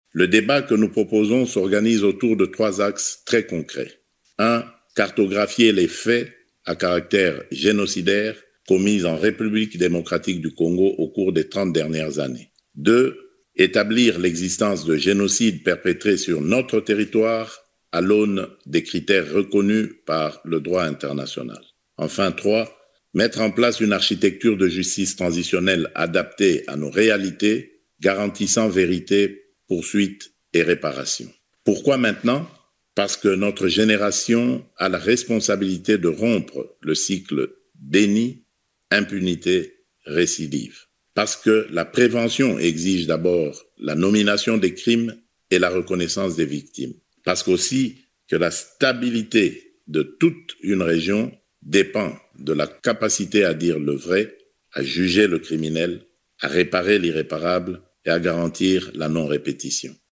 Il l’a affirmé ce lundi 8 septembre dans une vidéo enregistrée diffusée à Genève (Suisse) au cours de la conférence de haut niveau sur la reconnaissance des génocides commis en RDC au cours des trente dernières années.